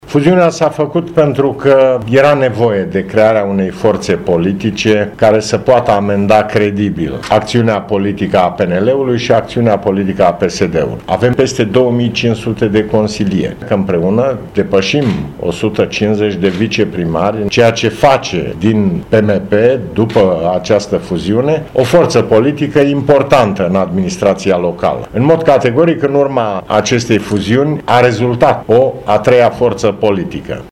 Președintele PMP, Traian Băsescu, s-a aflat astăzi în Poiana Brașov, unde i-a vizitat pe tinerii participanți la Școala de Vară a Tineretului din PMP. Cu acest prilej, Traian Băsescu le-a amintit participanților cele mai importante obiective pe care le are în vedere partidul, după fuziunea cu UNPR: